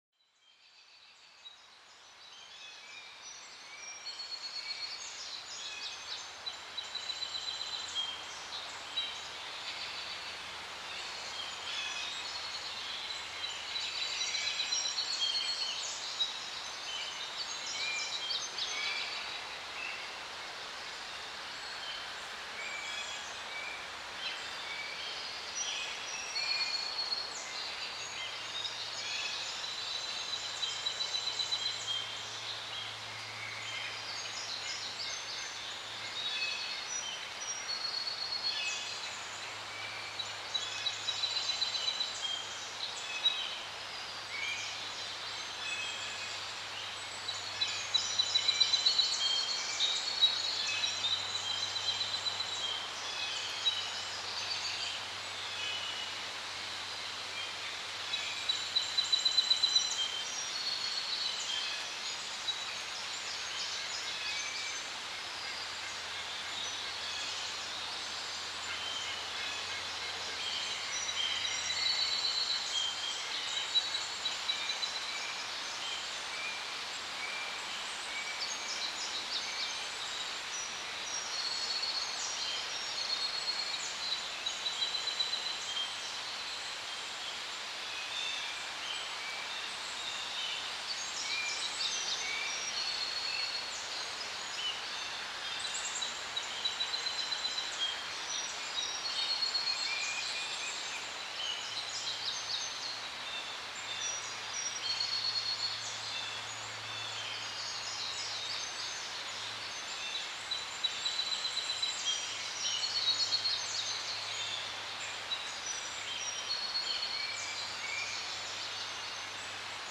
Forêt bercée par le vent : un souffle apaisant pour calmer l'esprit